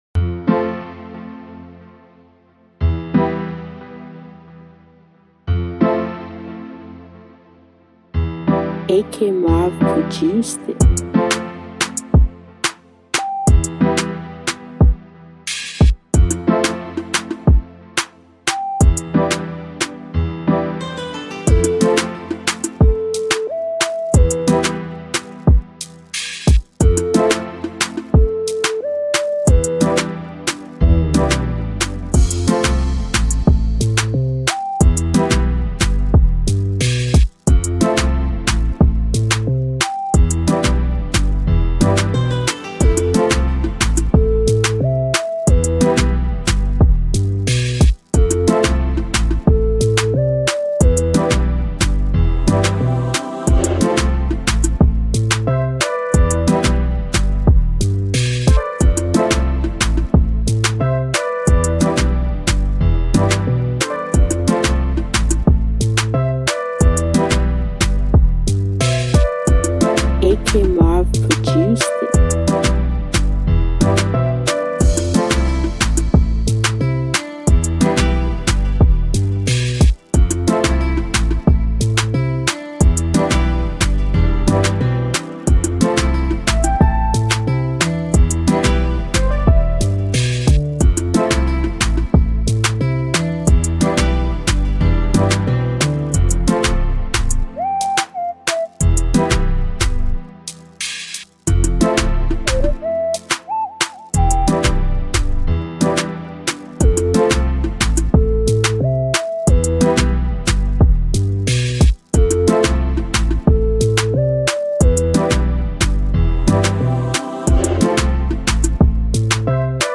beat Instrumental